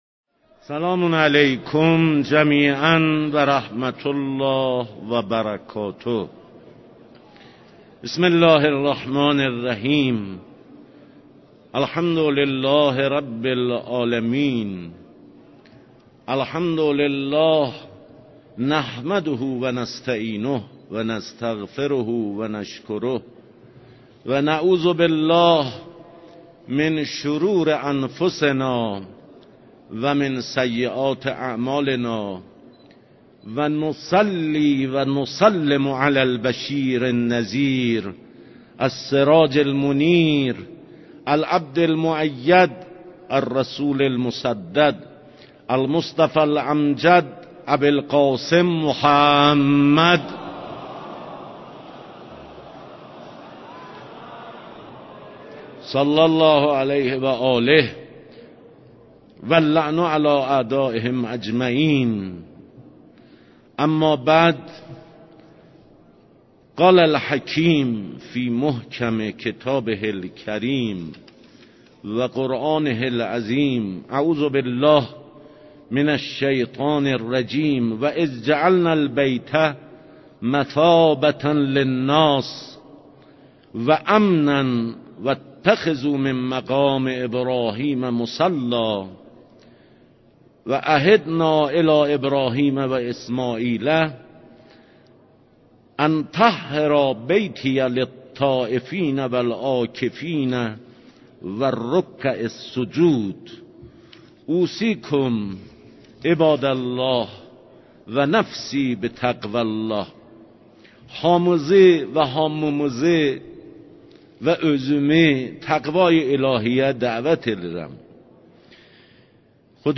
Təbrizin Cümə Namazınız Xütbələri 19 FEVRAL 2021